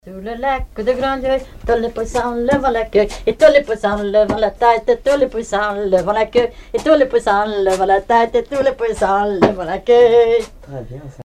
branle
Couplets à danser
collecte en Vendée
Répertoire de chants brefs et traditionnels
Pièce musicale inédite